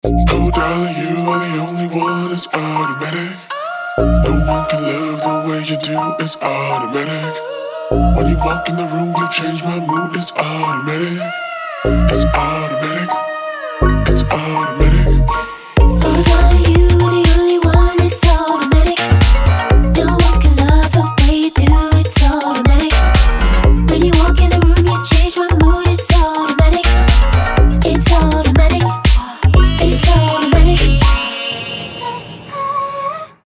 Клубные
Для любителей электронной обработки вокала
Метки: Electronic,